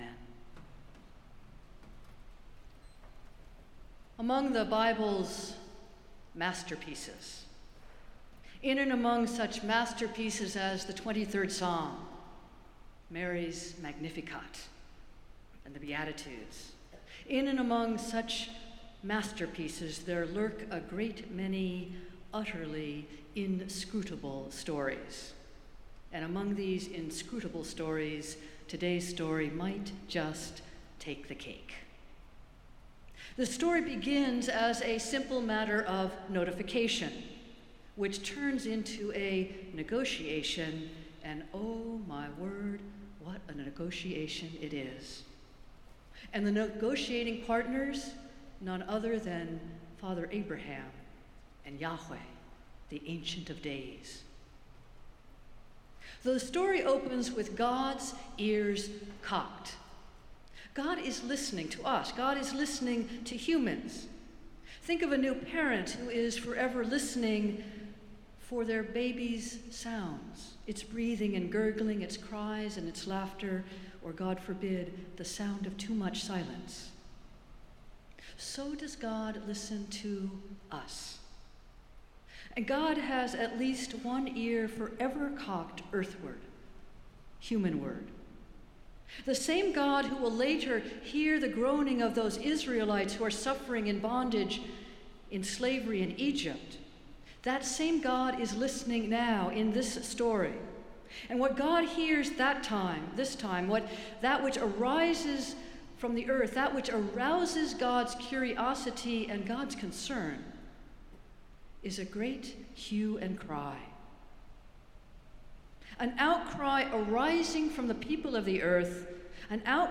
Festival Worship - Tenth Sunday after Pentecost